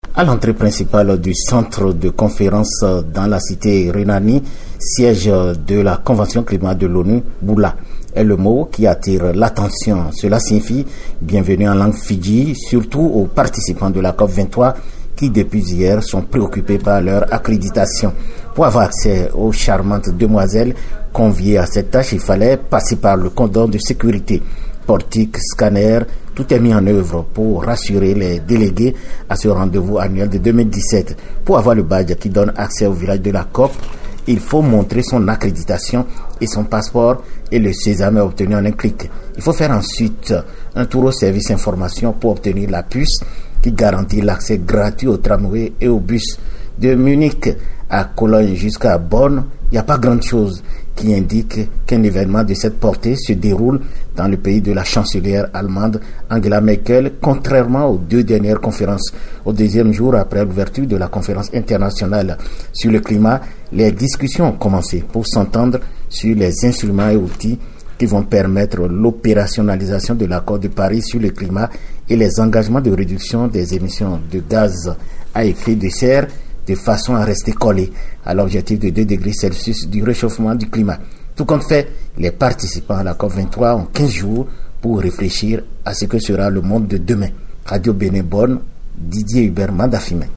Ambiance au début de cette COP23, Envoyé spécial Climate reporter à Bonn